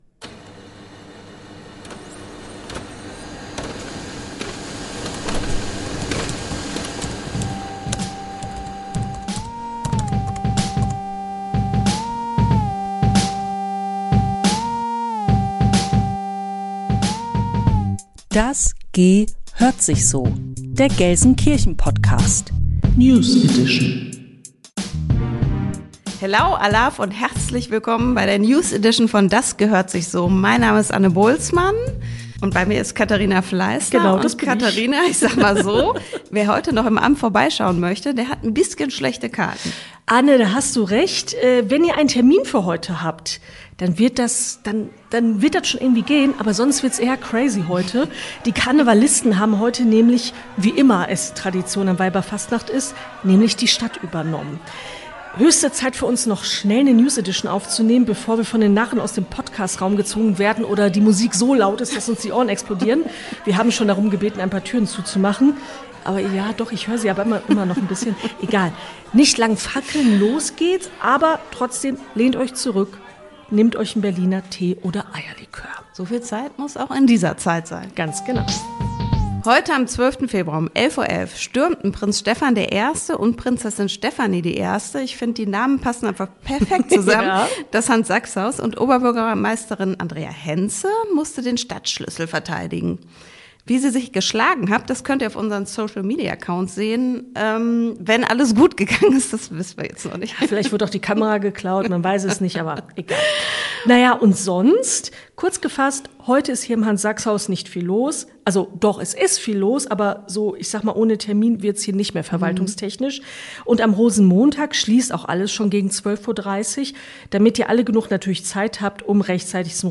Staatsregierung , Nachrichten , Gesellschaft & Kultur